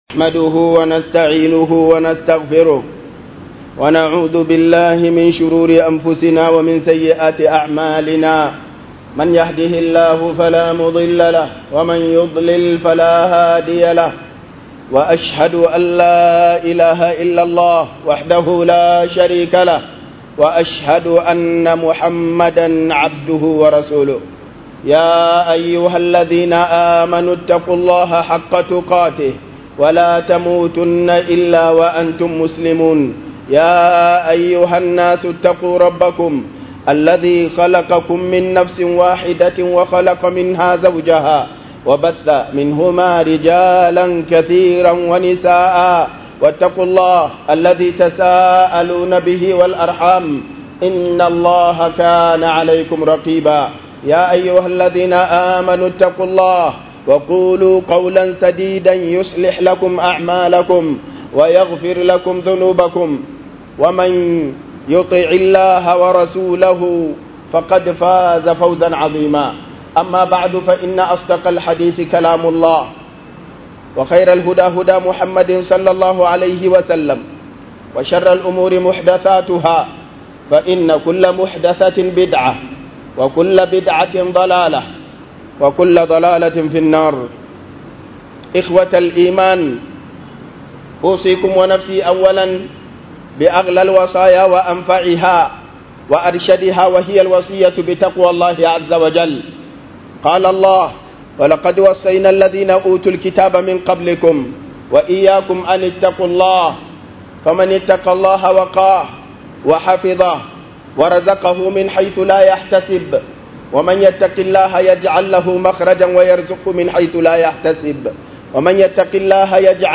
MUHIMMANCIN NEMAN ILIMIN - Huduba